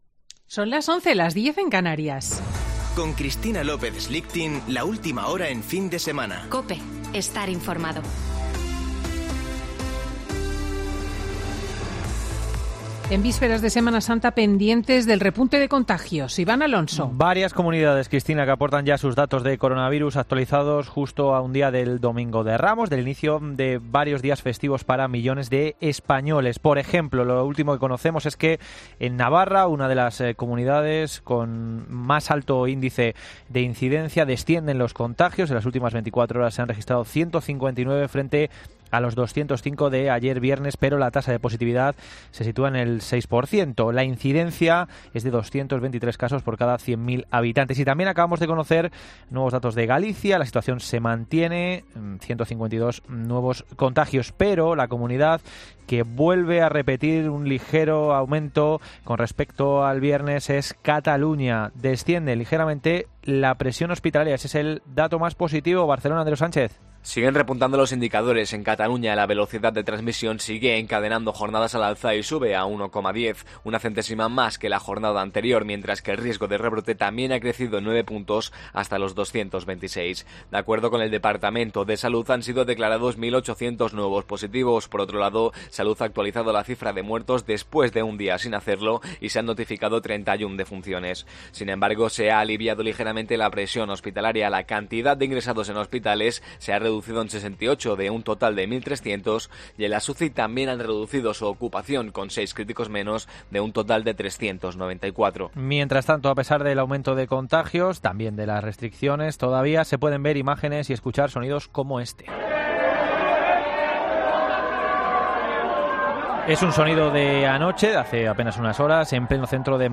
Boletín de noticias COPE del 27 de marzo de 2021 a las 11.00 horas